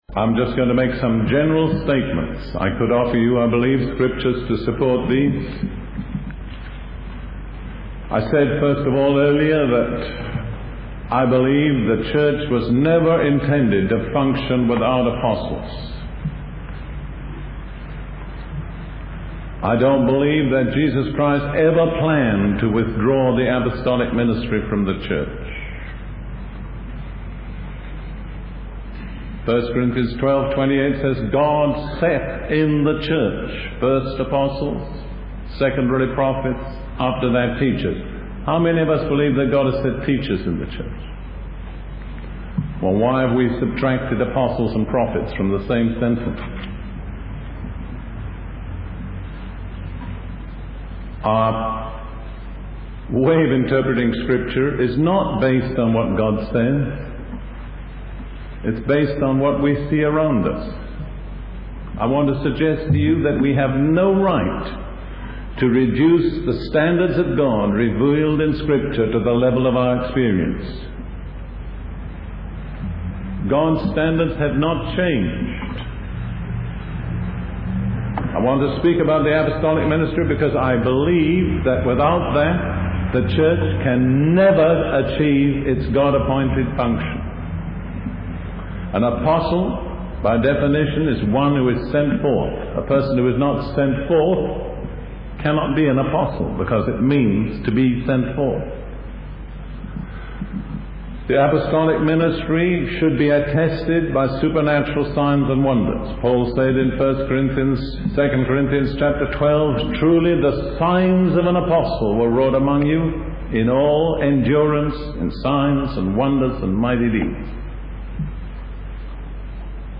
In this sermon, the speaker emphasizes the need for repentance and deep radical changes in the church and its leadership. They suggest that the current emphasis in the church is primarily on conservation rather than Apostolic outreach. The speaker also highlights the importance of understanding and caring for the Jewish people, using the Holocaust as a warning for the Gentiles.